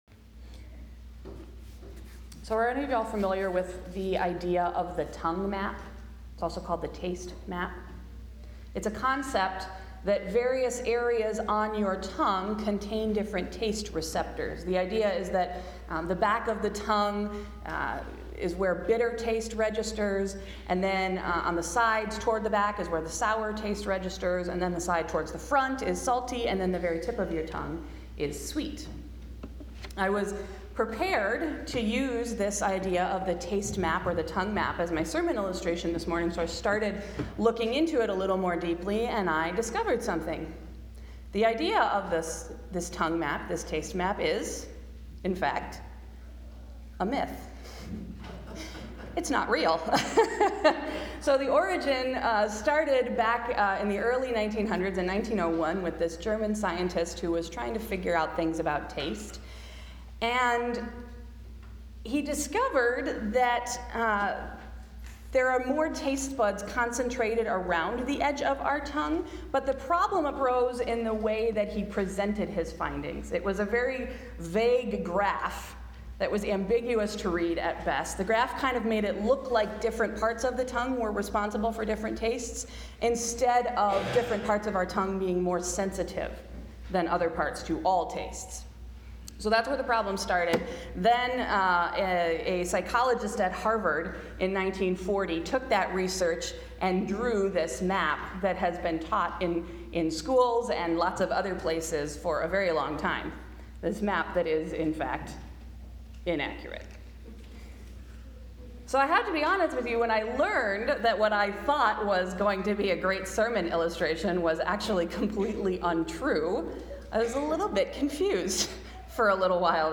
Sunday’s sermon: Joy That’s Bittersweet